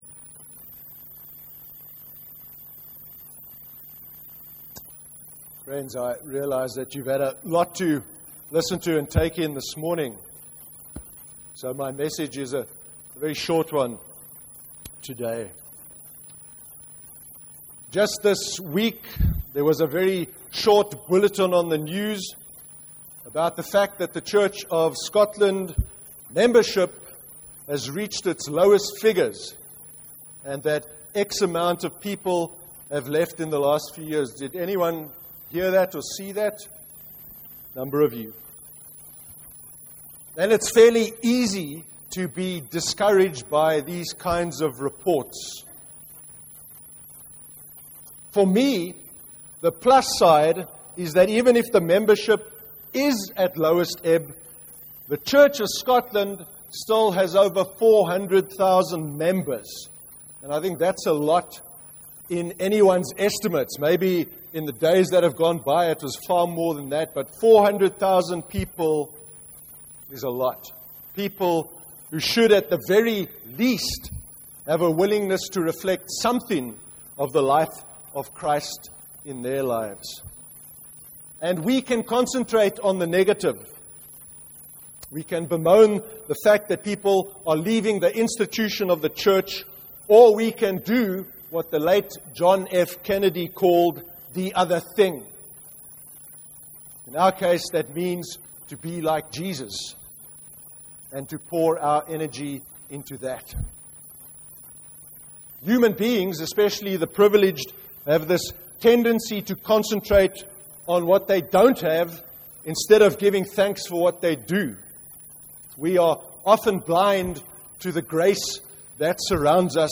04/05/2014 sermon. Being thankful and gracious